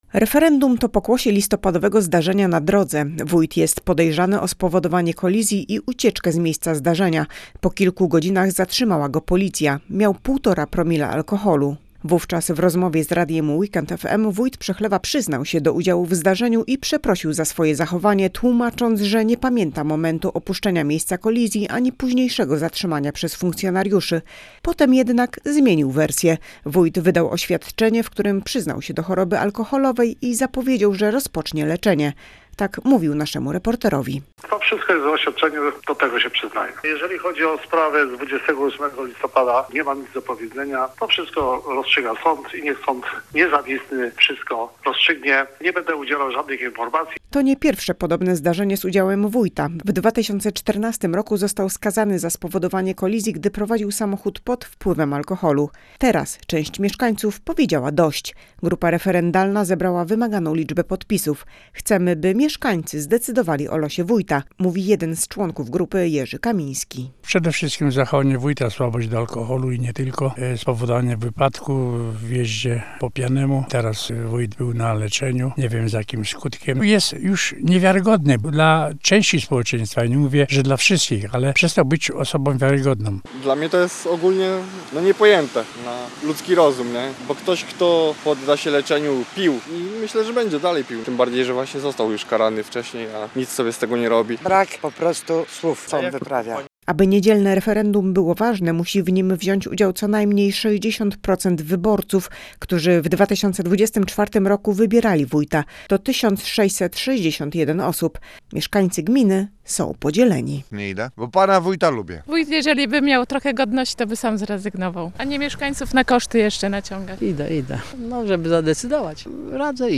Teraz Andrzej Żmuda-Trzebiatowski mówi naszej reporterce, że na referendum nie pójdzie.